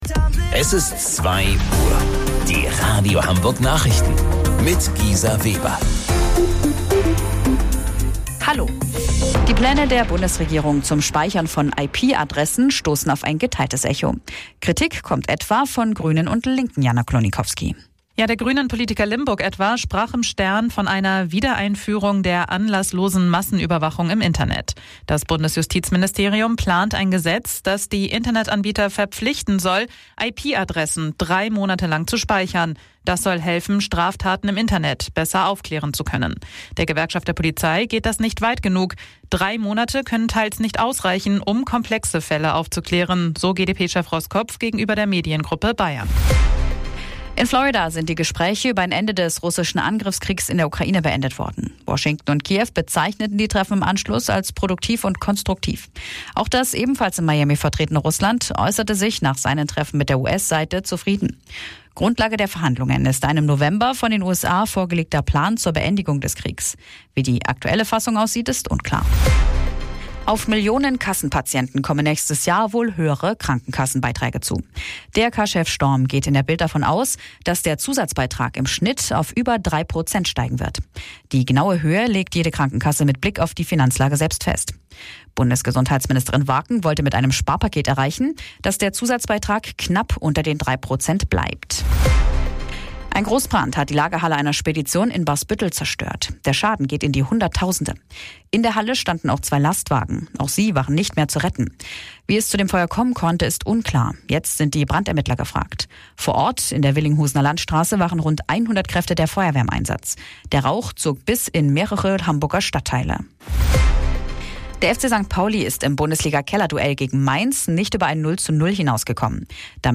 Radio Hamburg Nachrichten vom 22.12.2025 um 02 Uhr